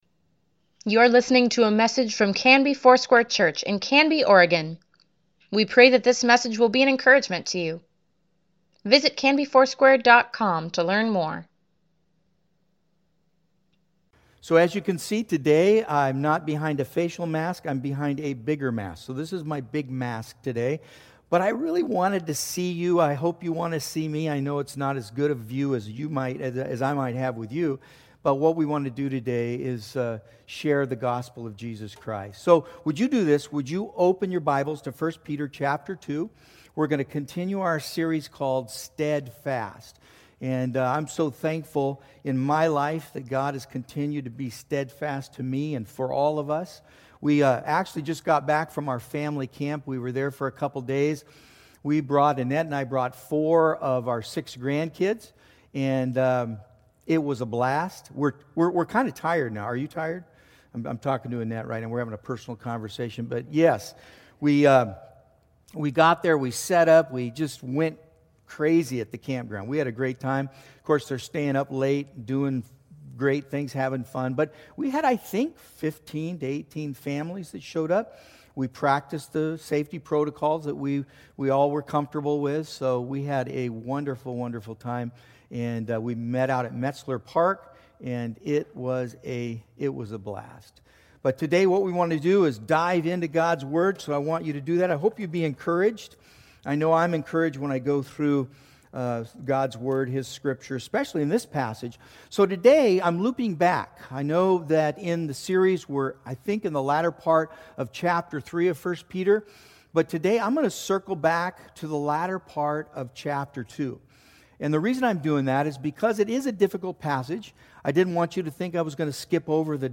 Weekly Email Water Baptism Prayer Events Sermons Give Care for Carus Steadfast, pt. 9 August 2, 2020